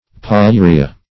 Polyuria \Pol`y*u"ri*a\, n. [NL.